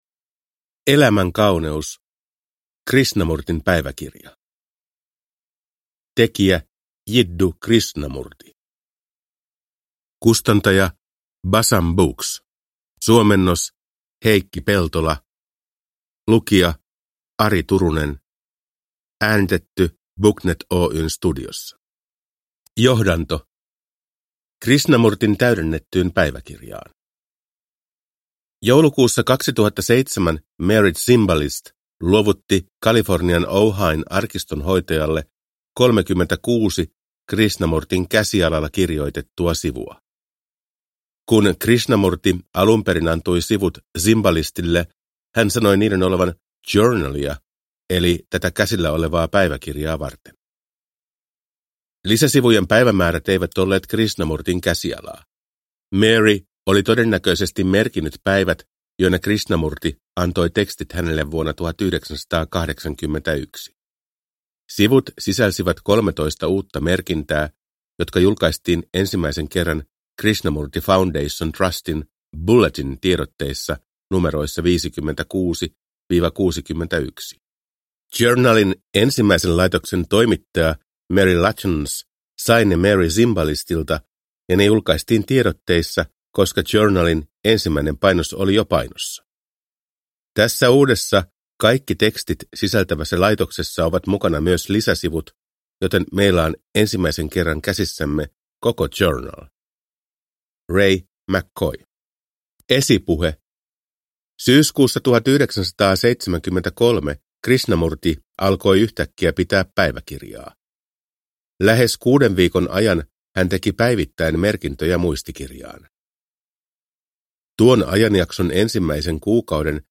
Elämän kauneus – Ljudbok